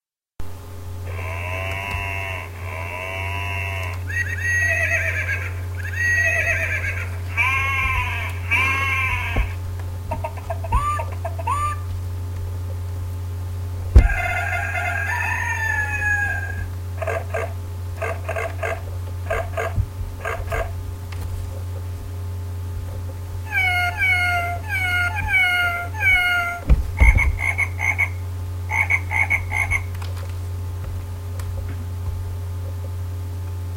onomatopeies
onomatopeies.mp3